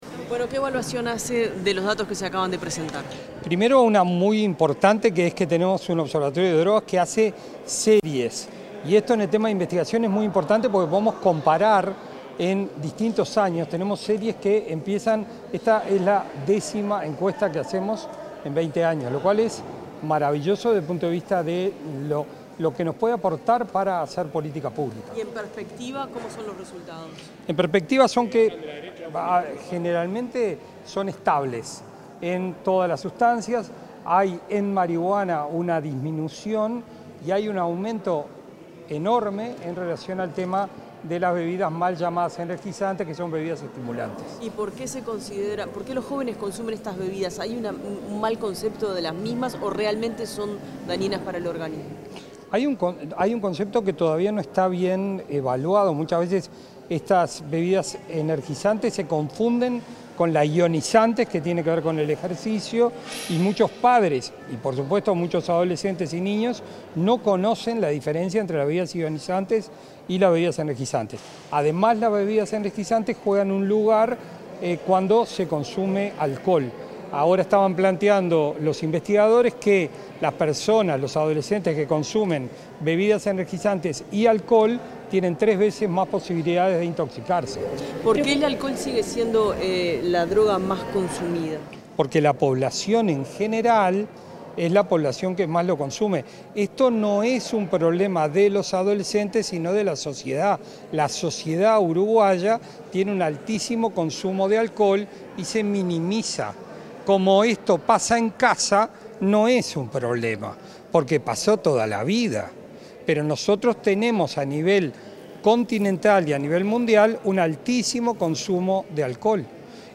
Declaraciones del secretario general de la Junta Nacional de Drogas, Gabriel Rossi